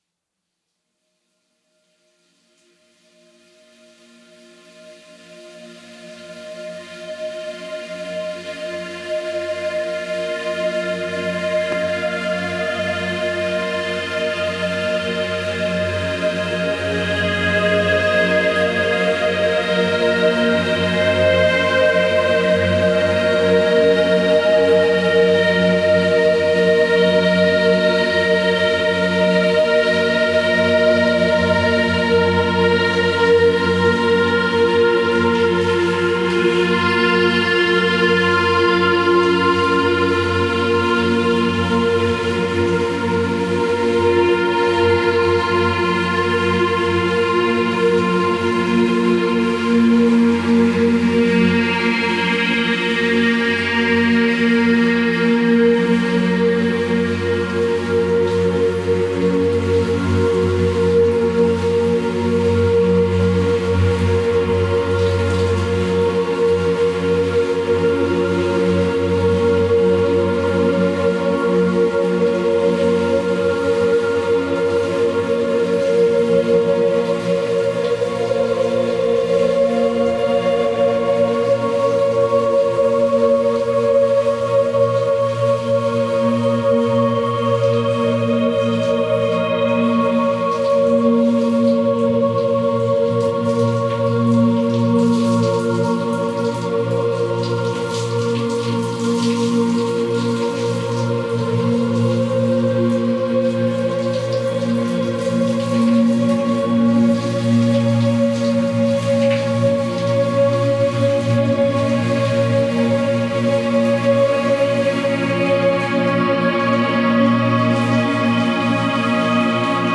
ambient/drone music project